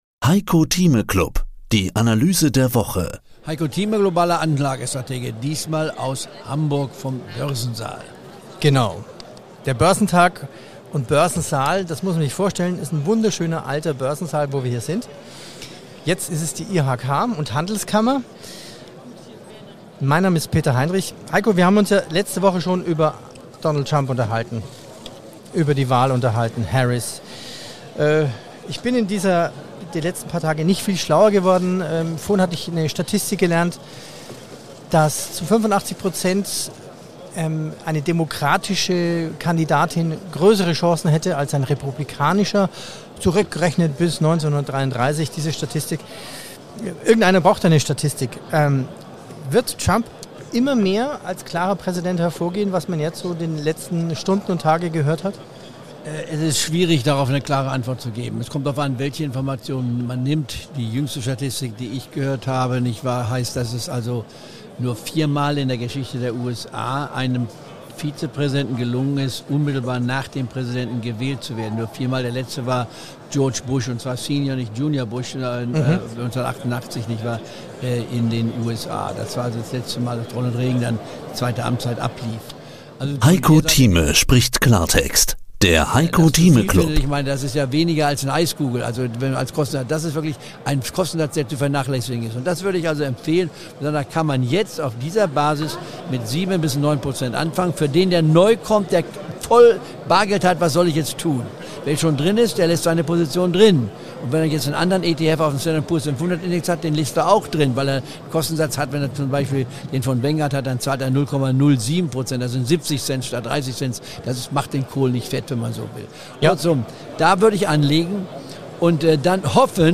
Diesmal war der Börsentag 2024 in Hamburg. Sie hören den Heiko Thieme Club mit Empfehlung und unten zusätzlich als Bonus eingefügt die Diskussions-Schlussrunde mit Heiko Thieme.